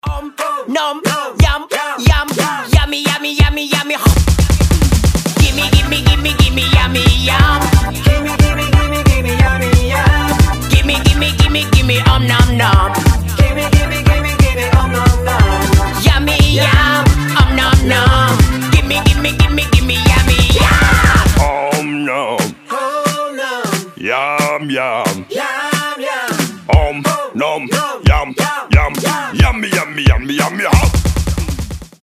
• Качество: 320, Stereo
позитивные
забавные
веселые
Детские песни на Ютубе